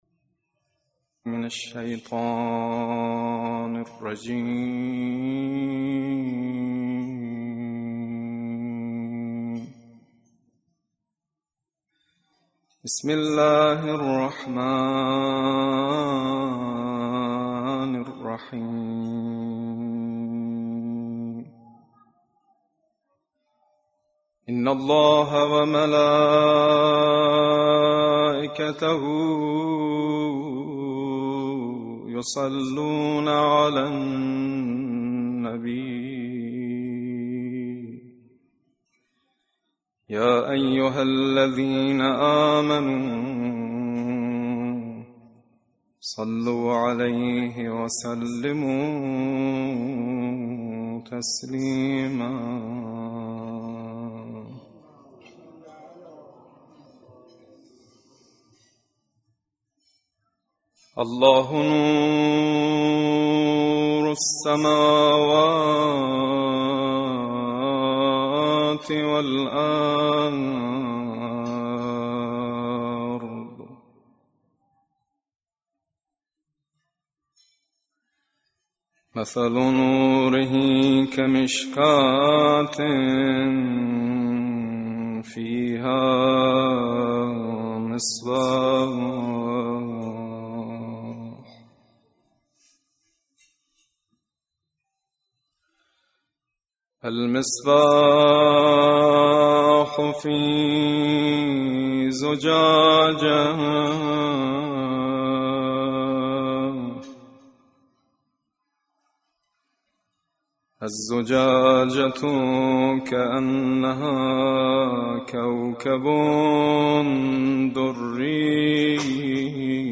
مراسم ارتحال آیةاللَه سیّد محمّد محسن حسینی طهرانی (1) - مدائح و مراثی - مداح شماره 1 | مکتب وحی
مراسم ارتحال آیةاللَه سیّد محمّد محسن حسینی طهرانی (1)
2310_Khatm_Ayatollah_Tehrani_Quran1.mp3